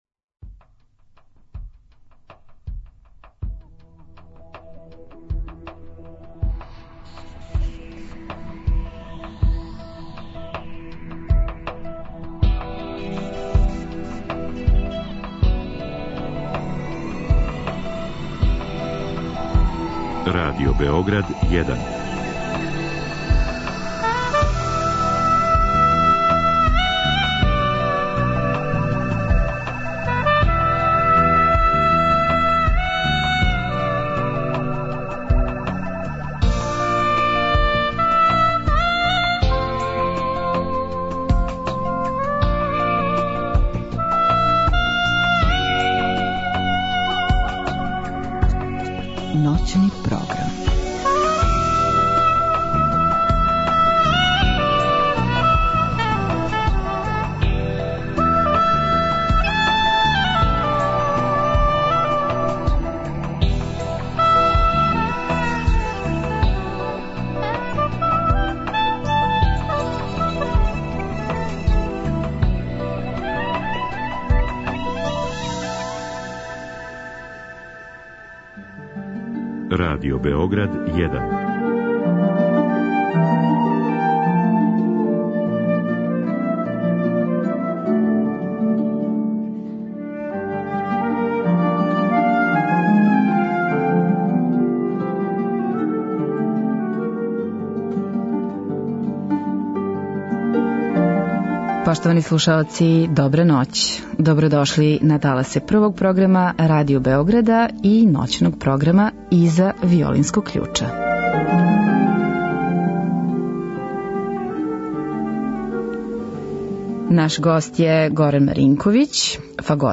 Уживо од 00-02.
Од другог часа после поноћи слушамо балетску музику Чајковског, Адама, Делиба и Прокофјева као и дела Демерсемана, Моцарта, Дворжака и Мјасковског.